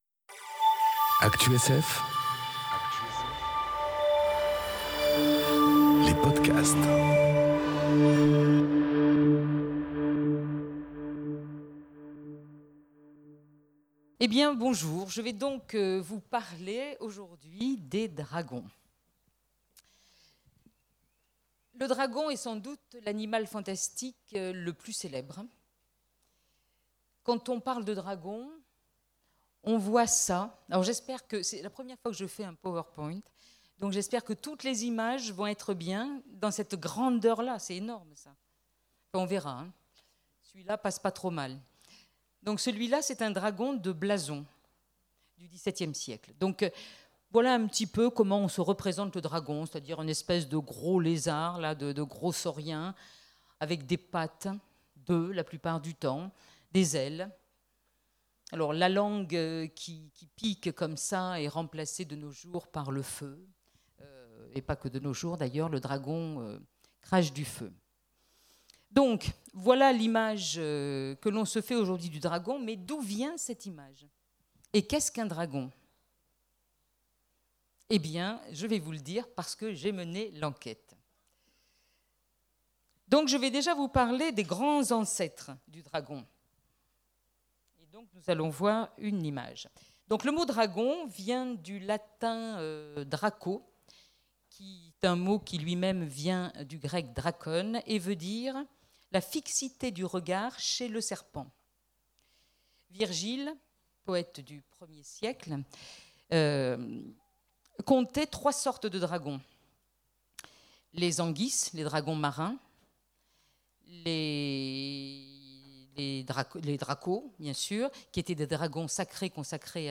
Conférence Enquête sur les dragons enregistrée aux Imaginales 2018